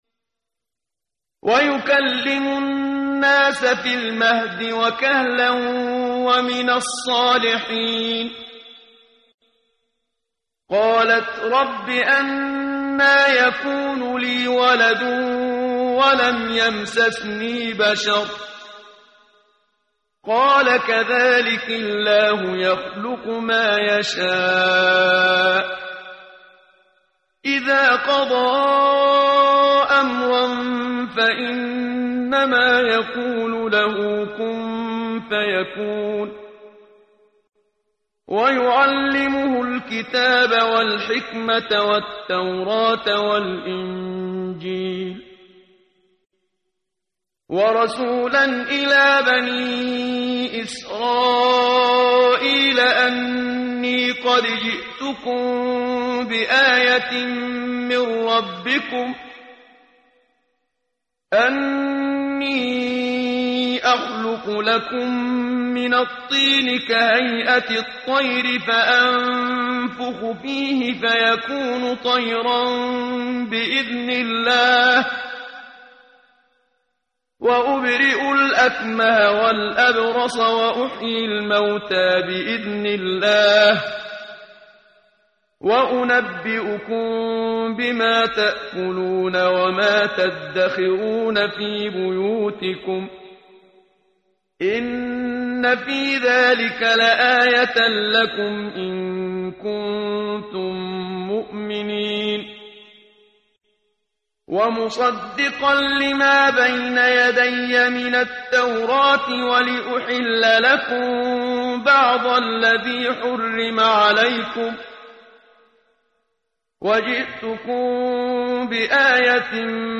ترتیل صفحه 56 سوره مبارکه آل عمران (جزء سوم) از سری مجموعه صفحه ای از نور با صدای استاد محمد صدیق منشاوی
quran-menshavi-p056.mp3